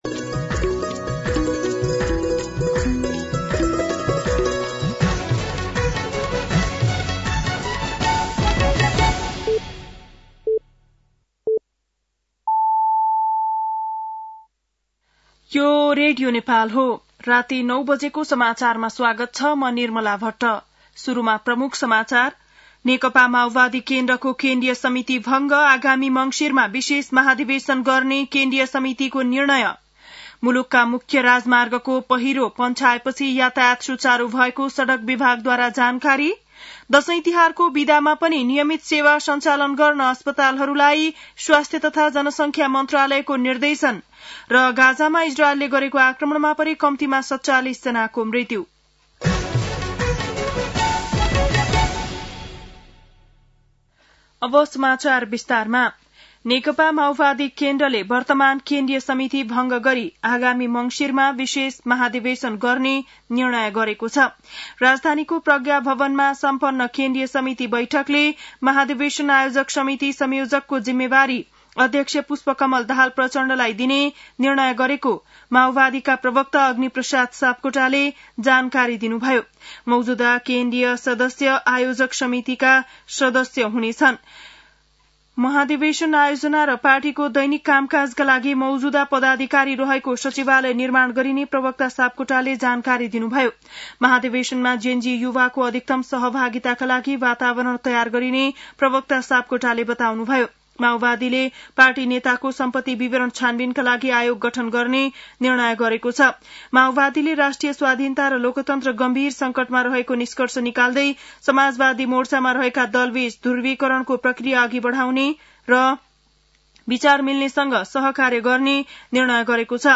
बेलुकी ९ बजेको नेपाली समाचार : १० असोज , २०८२